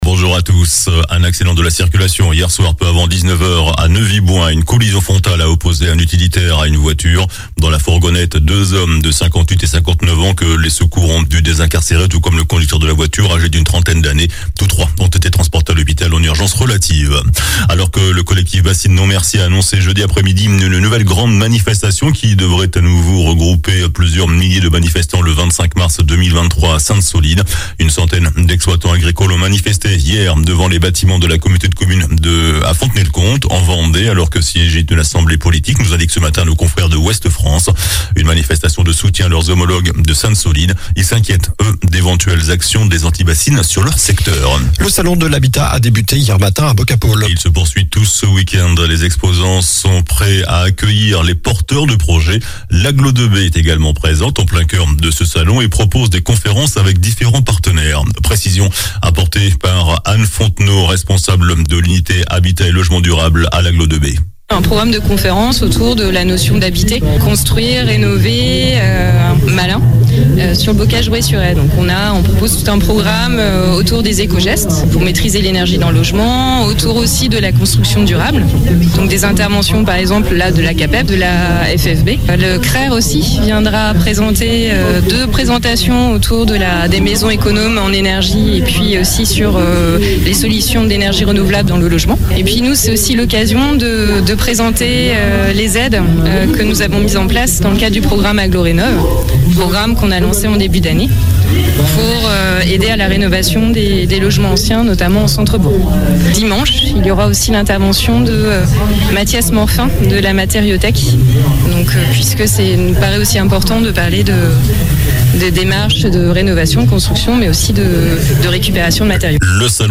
JOURNAL DU SAMEDI 19 NOVEMBRE